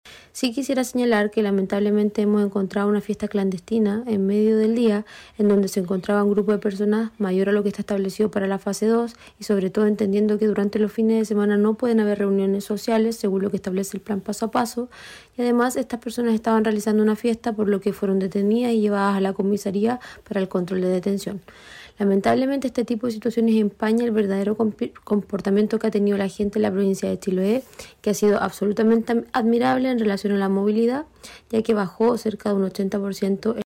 Así lo informó la jefa de la Autoridad Sanitaria, María Fernanda Matamala, quien valoró la excelente conducta de las personas, que acataron las disposiciones dispuestas por la autoridad, como también lo hizo el comercio.